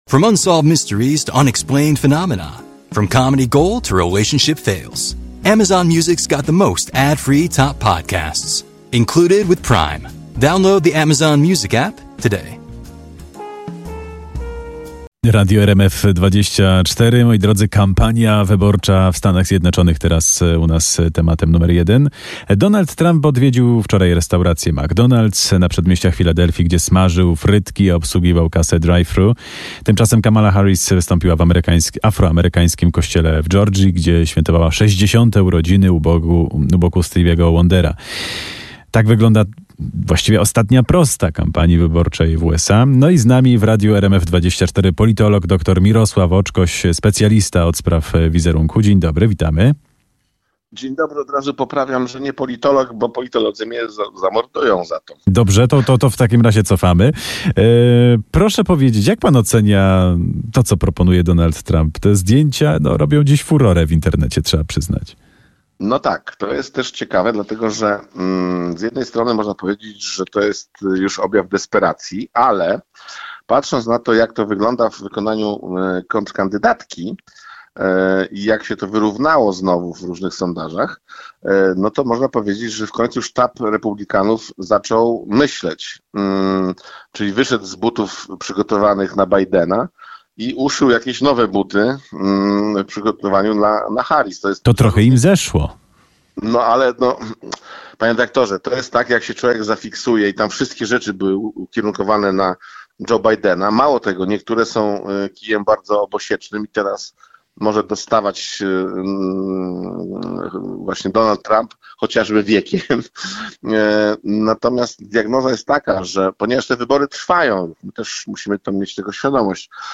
Odsłuchaj starsze transmisje RMF FM!
18:00 Fakty i Popołudniowa rozmowa w RMF FM - 21.10.2024